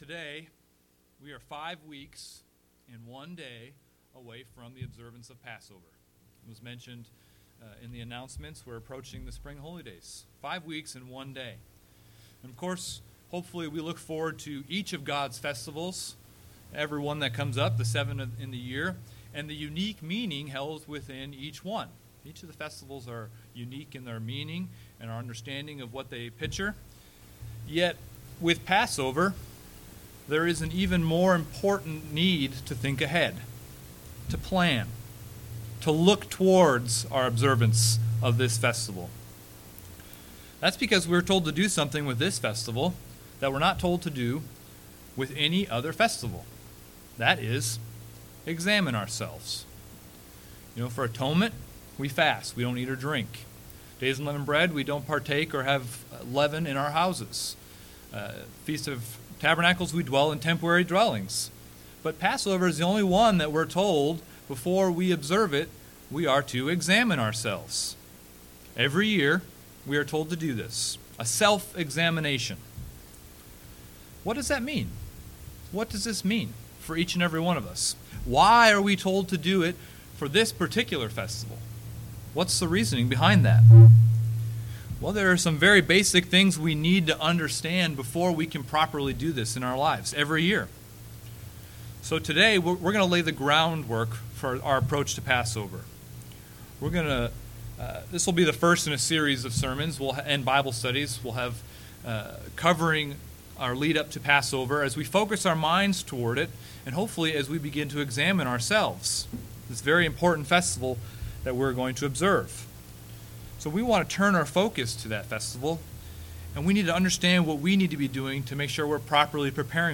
However, in order to do this properly we should have a basic understanding of why we do this, what we are to examine for, and how to go about doing our examinations. This sermons looks to lay the foundation to answer these questions by going to the word of God.
Given in Springfield, MO